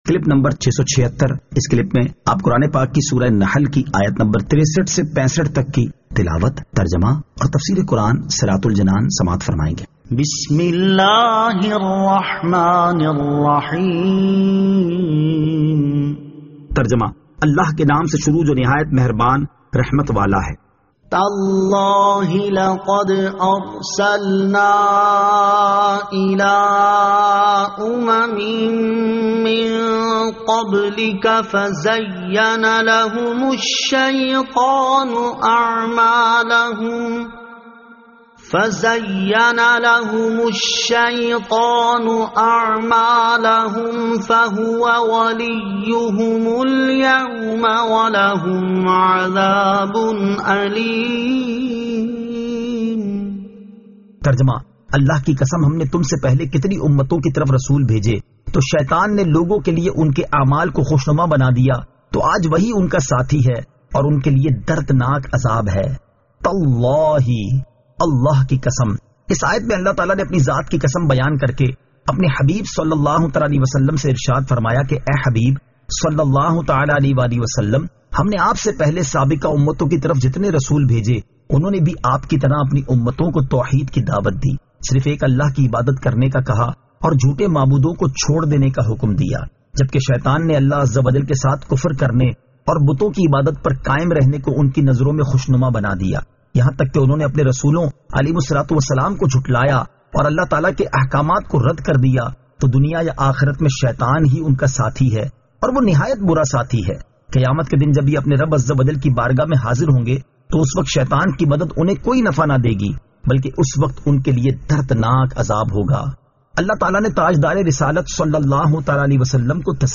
Surah An-Nahl Ayat 63 To 65 Tilawat , Tarjama , Tafseer